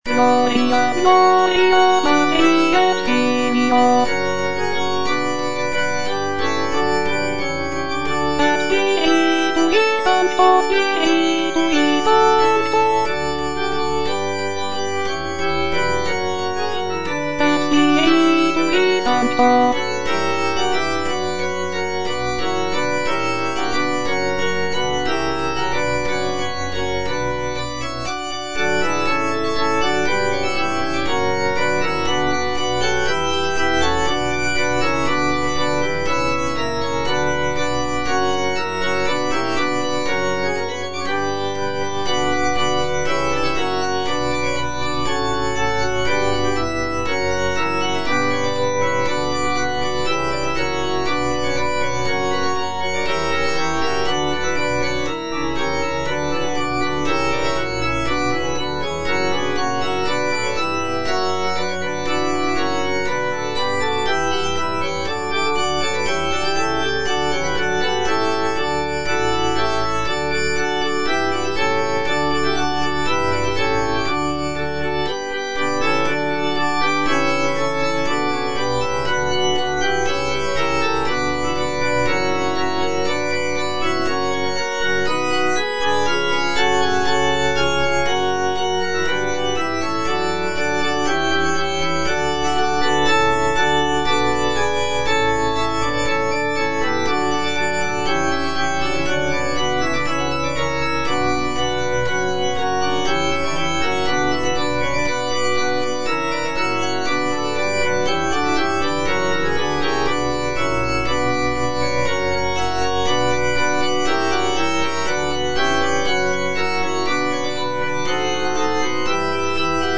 petit choeur) - Alto (Voice with metronome) Ads stop
sacred choral work